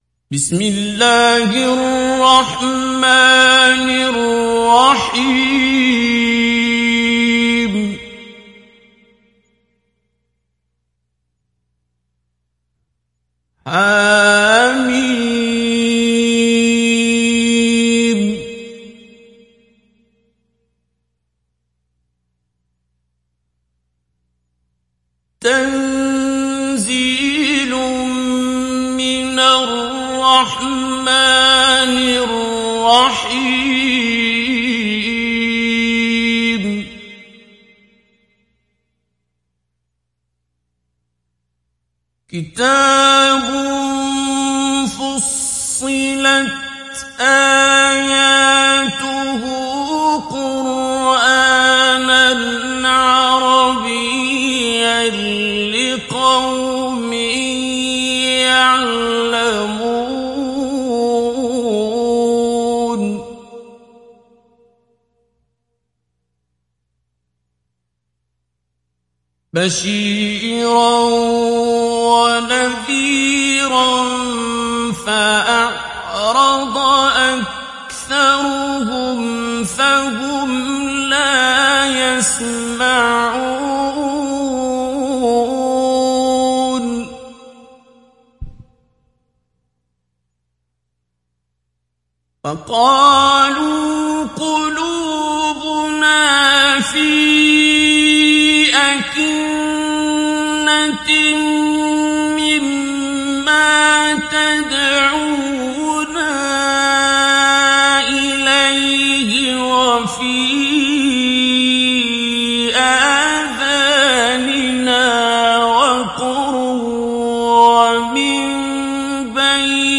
دانلود سوره فصلت mp3 عبد الباسط عبد الصمد مجود روایت حفص از عاصم, قرآن را دانلود کنید و گوش کن mp3 ، لینک مستقیم کامل
دانلود سوره فصلت عبد الباسط عبد الصمد مجود